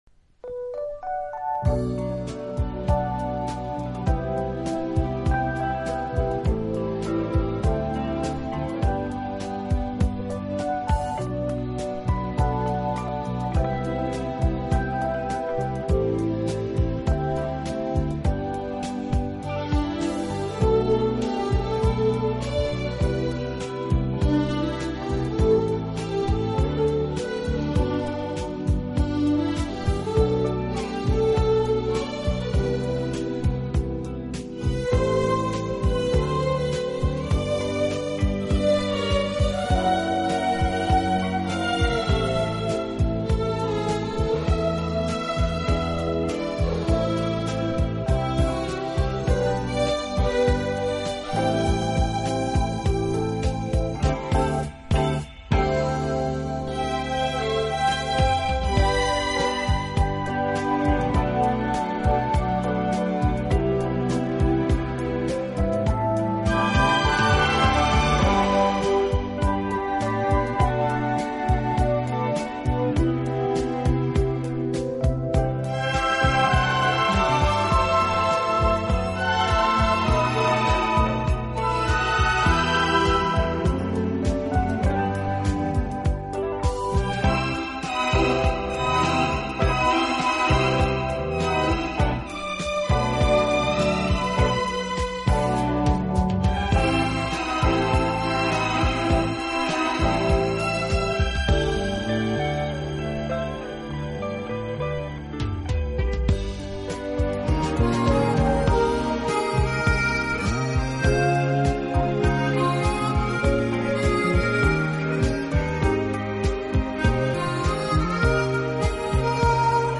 轻快、节奏鲜明突出，曲目以西方流行音乐为主。